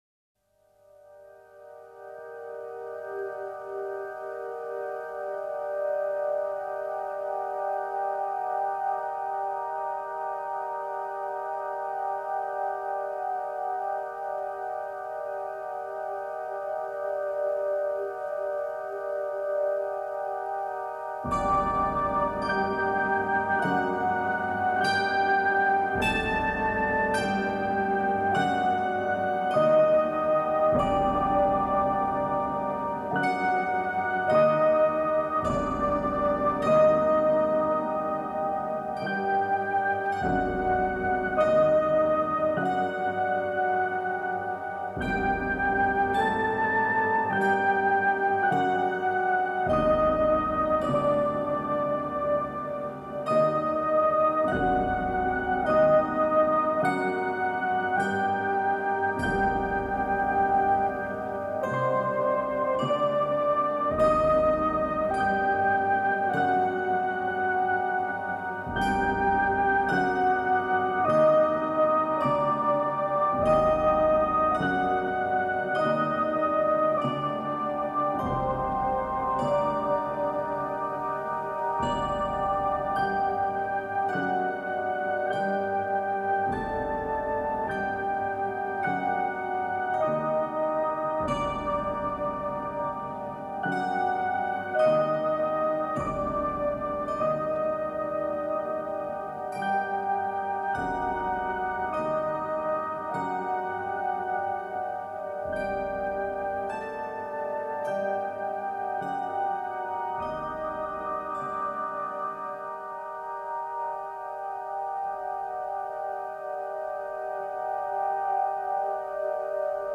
قطعه ای از موسیقی متن